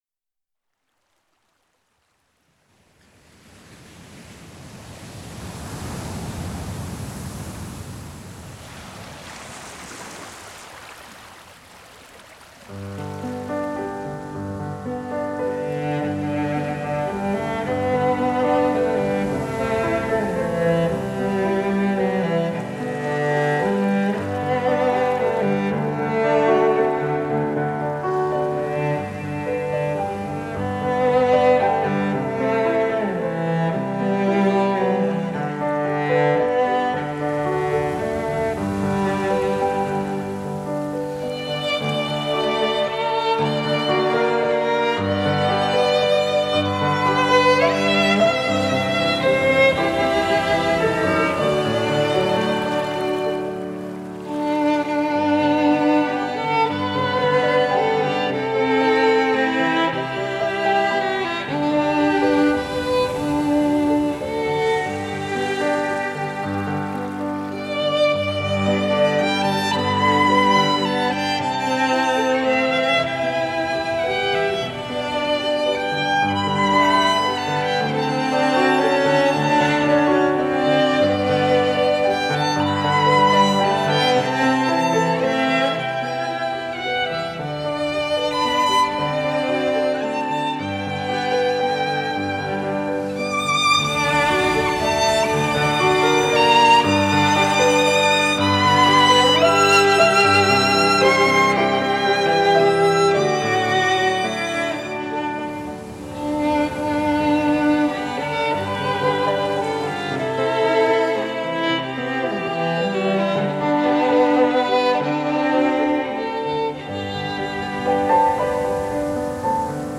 醉飲古典的醇酒，聆賞幽雅的樂聲；
傾聽細膩的音符，尋訪寧靜的心靈…
小提琴
大提琴
雙簧管
長笛
鋼琴